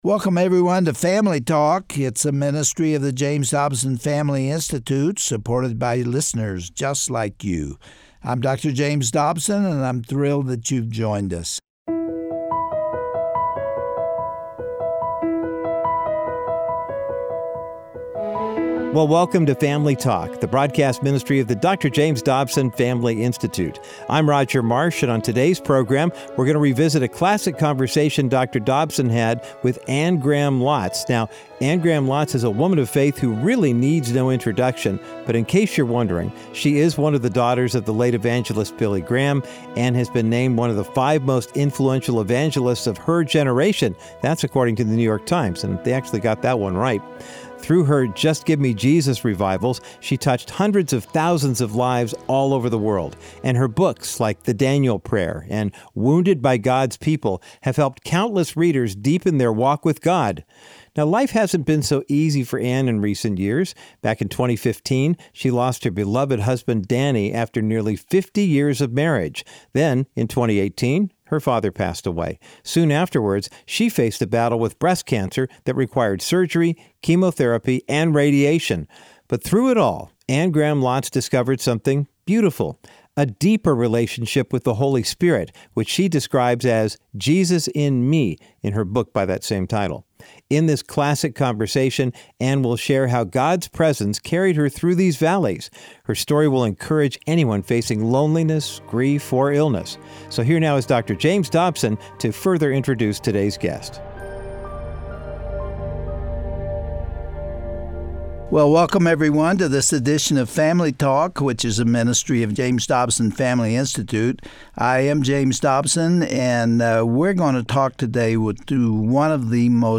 Host Dr. James Dobson
Guest(s):Anne Graham Lotz